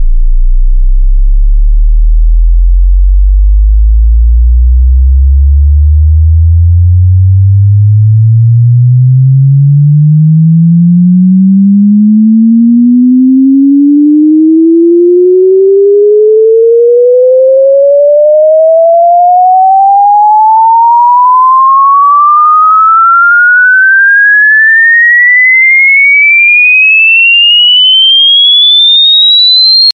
反向上升和下降 Fx
描述：诗词起伏fx
Tag: 140 bpm Trance Loops Fx Loops 2.61 MB wav Key : Unknown